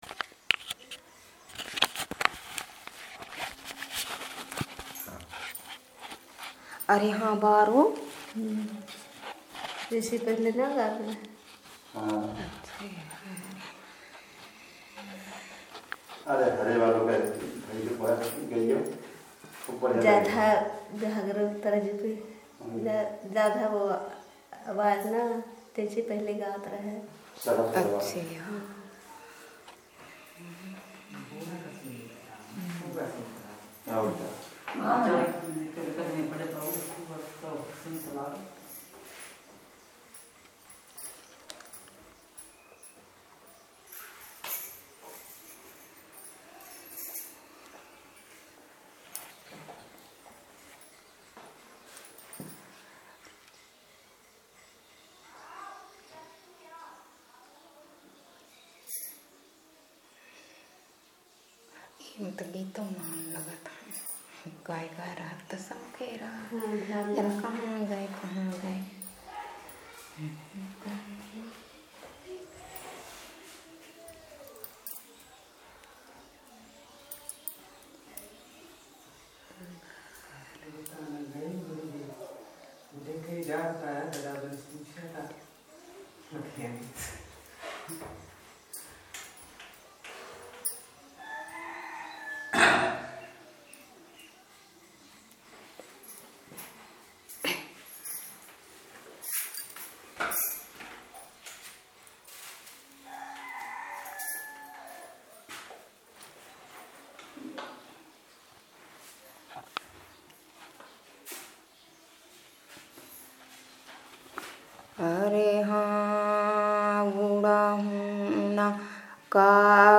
Performance of a song